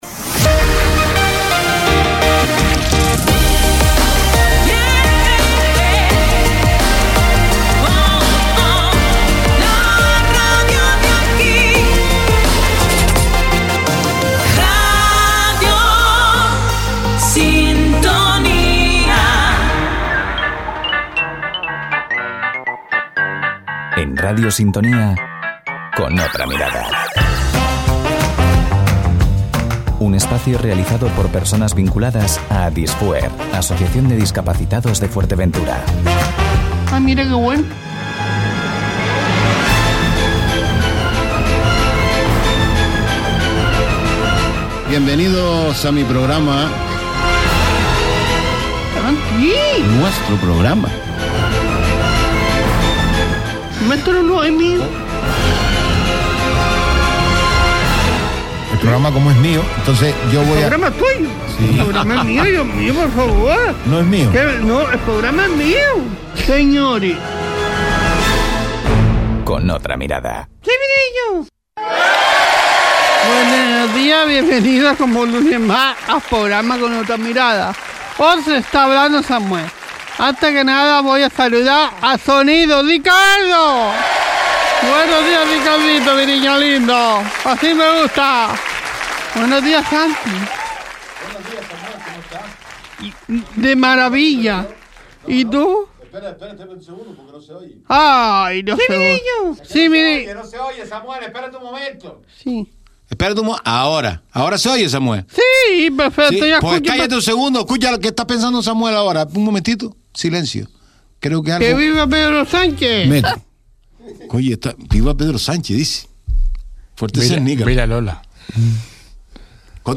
¡Una charla sin filtros que no te puedes perder!